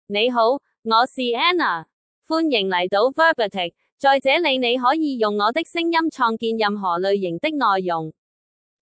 Anna — Female Chinese (Cantonese, Hong Kong) AI Voice | TTS, Voice Cloning & Video | Verbatik AI
FemaleChinese (Cantonese, Hong Kong)
Anna is a female AI voice for Chinese (Cantonese, Hong Kong).
Voice sample
Female
Anna delivers clear pronunciation with authentic Cantonese, Hong Kong Chinese intonation, making your content sound professionally produced.